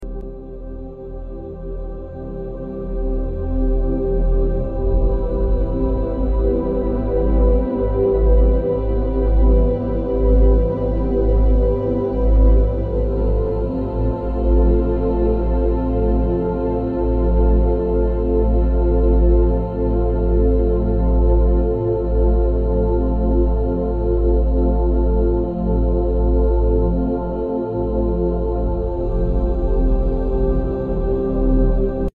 The soul star chakra is the eighth chakra and exists in your etheric body above the crown chakra. Represented here with the golden merkaba, This sound healing track contains the healing frequency for your soul star chakra.